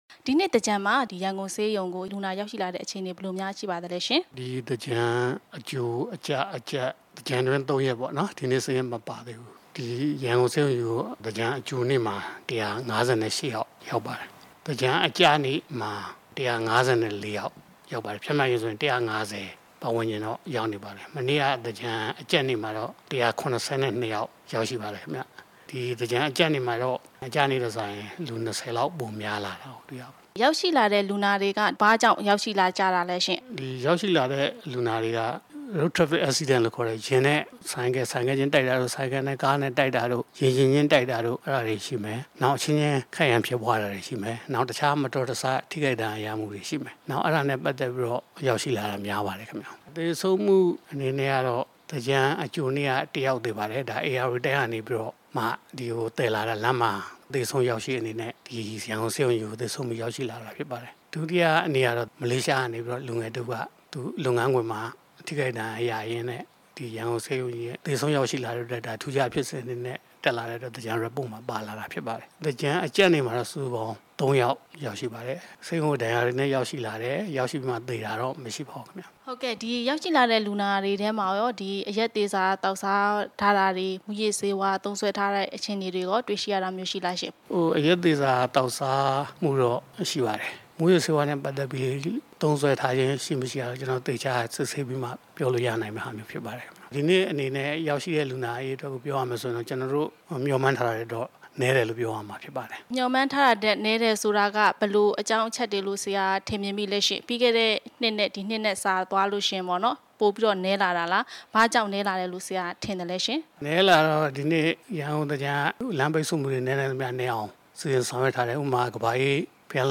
တွေ့ဆုံမေးမြန်း ထားပါတယ်။